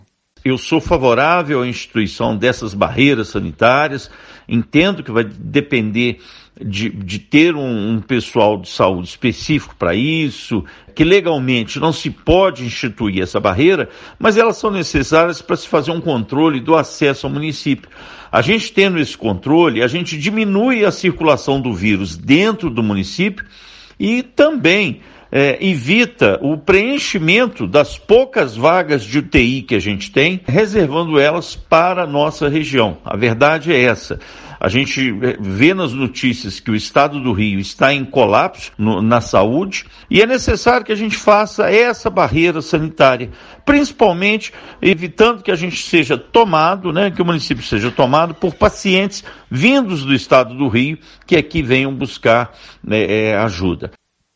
vereador José Márcio